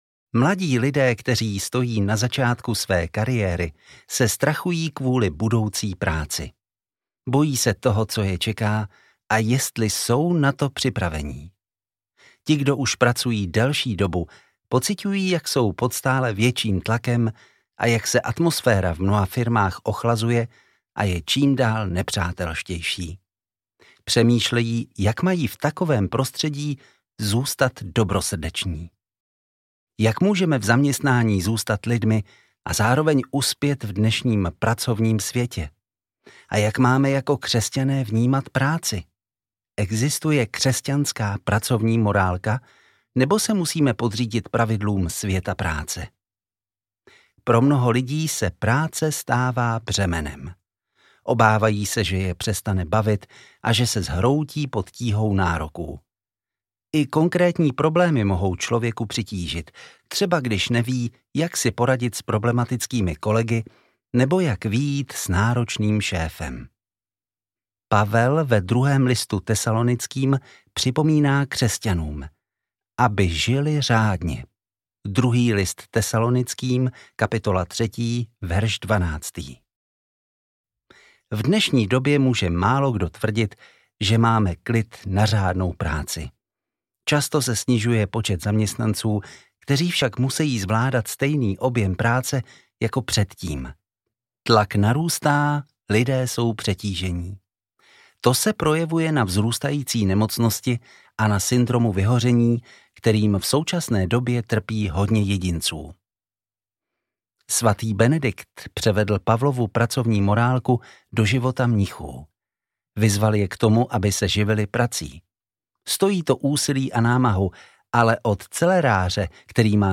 Žijte nejen o víkendu audiokniha
Ukázka z knihy